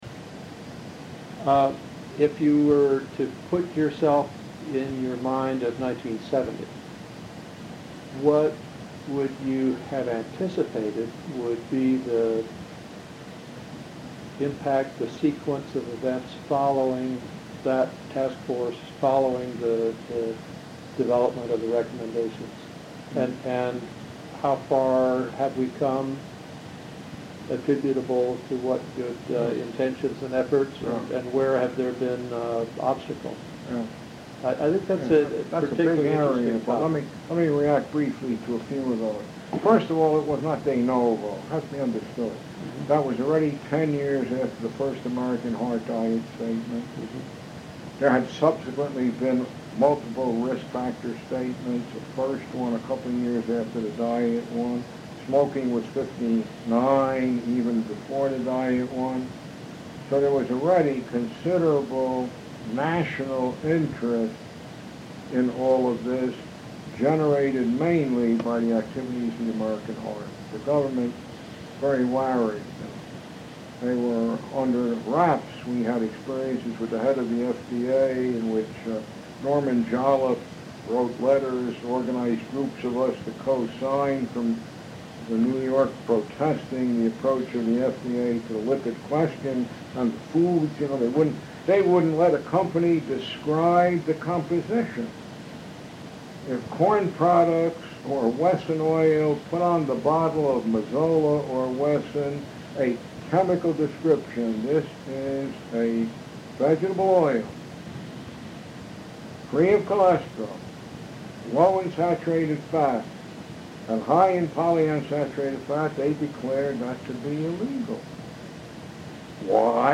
Year: 2002 Location: Chicago, Illinois